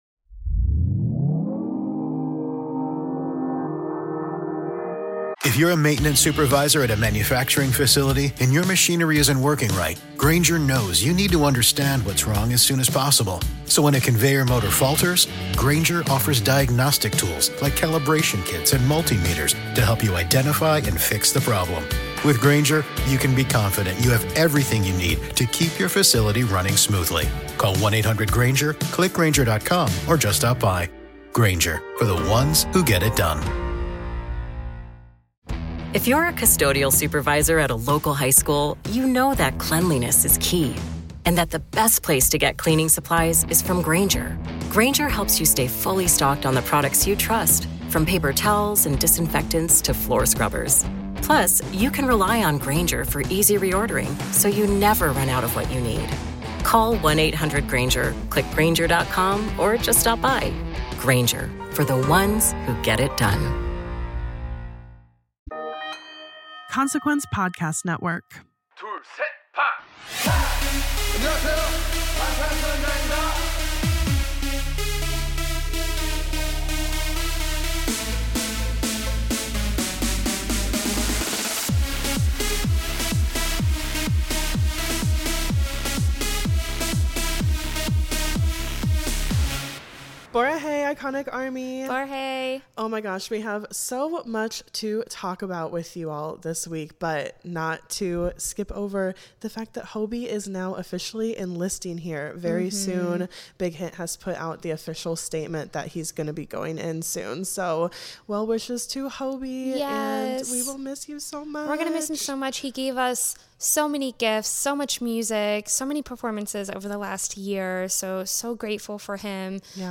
Music, Music Commentary, Society & Culture, News, Entertainment News